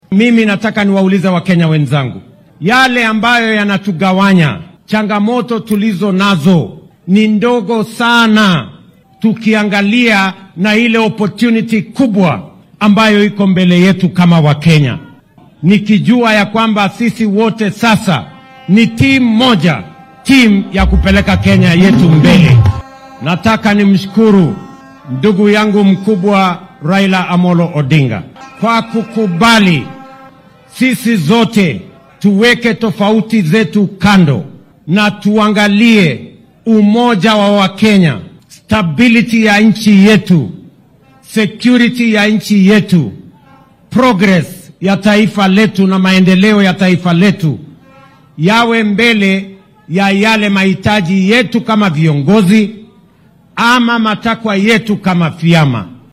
Madaxweynaha dalka William Ruto ayaa xilli uu ku sugnaa ismaamulka Siaya uu sheegay in tallaabadii uu golihiisa wasiirrada ugu soo daray afar ka mid ah siyaasiyiinta xisbiga mucaaradka ee ODM ay ahayd mid istaraatiiji ah oo looga gol-leeyahay xoojinta midnimada qaran, nabad galyada iyo baraaraha dalka. Waxaa uu kenyaanka ku boorriyay in ay midnimada ku dadaalaan.